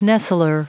Prononciation du mot nestler en anglais (fichier audio)
Prononciation du mot : nestler